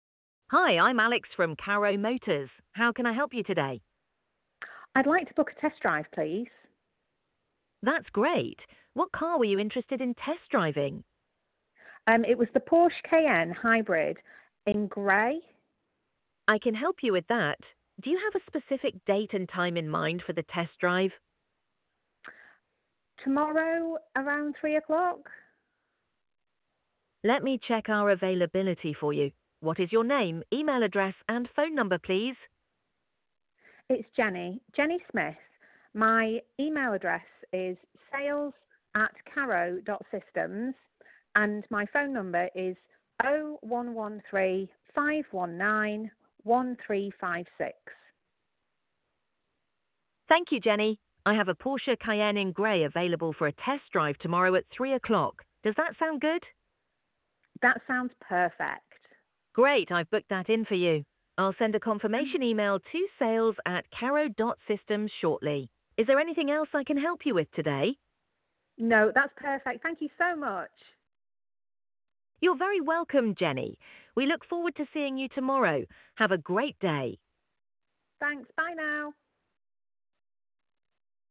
AI Voice Agent